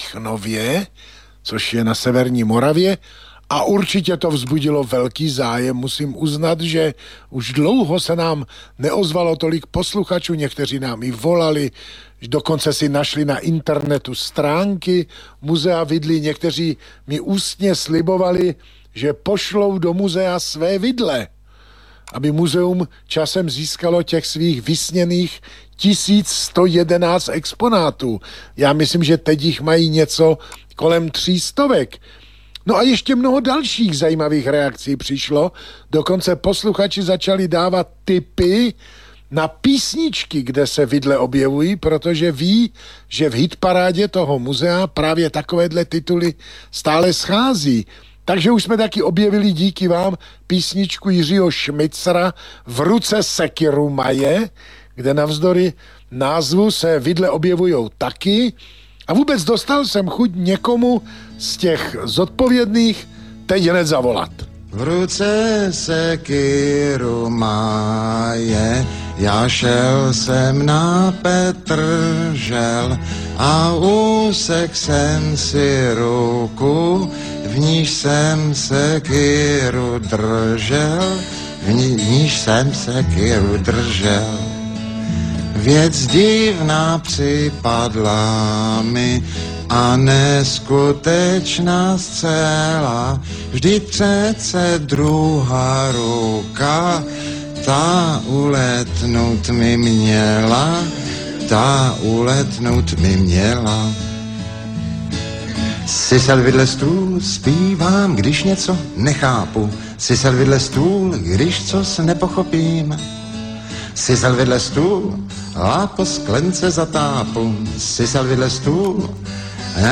A protože asi nestihnete z objektivních důvodů reprízu pořadu hned krátce po Silvestru v pondělí 1:00-2:00, můžete si ho pustit ze záznamu zde :) ... a jako bonus uslyšíte dvě písně o vidlích.